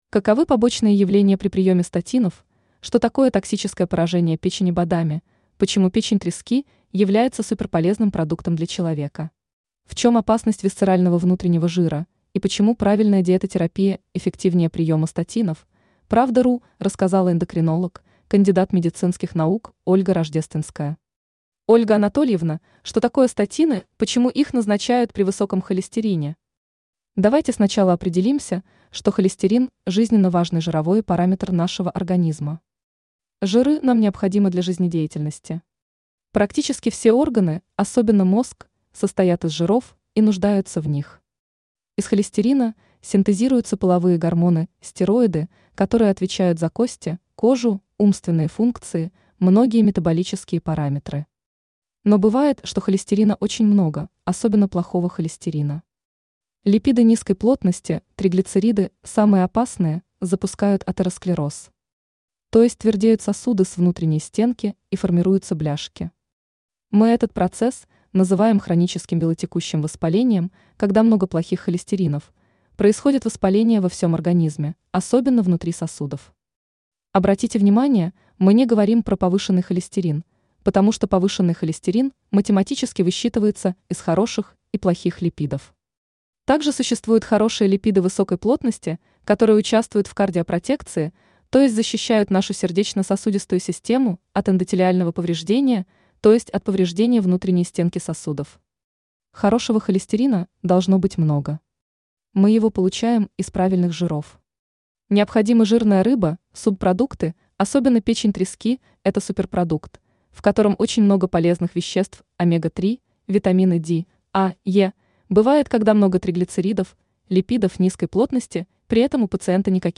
скачать интервью в txt формате